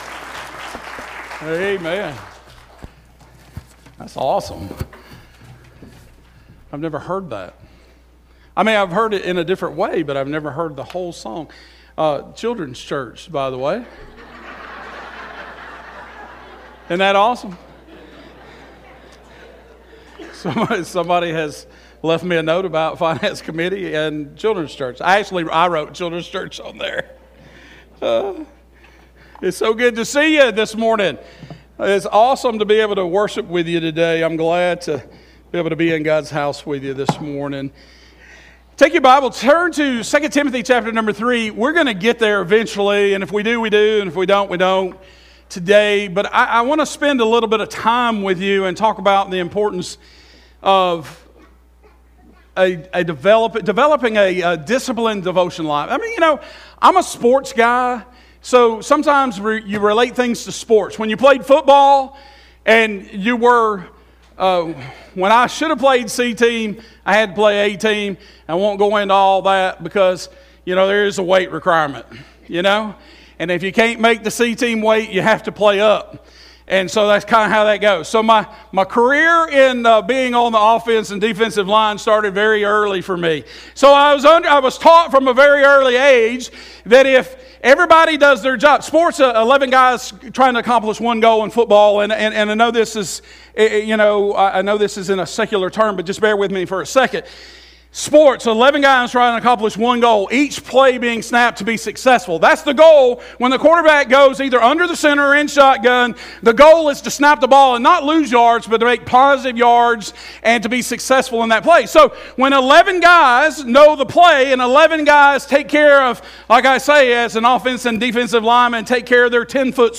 First Baptist Church - Sermons